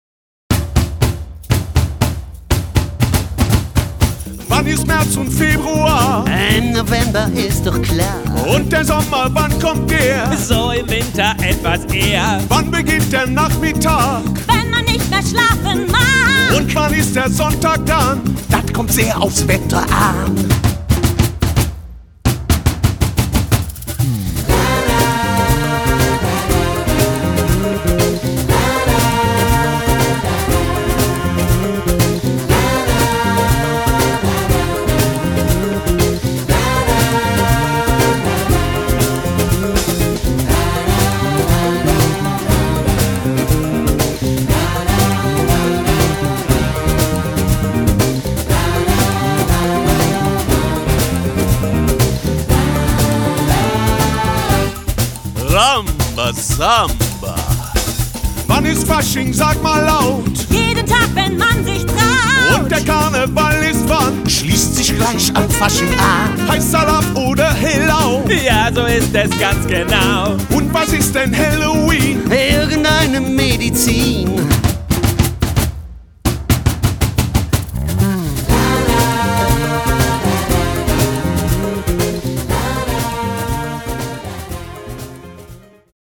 Eine CD mit allen Liedern und der Geschichte zum Anhören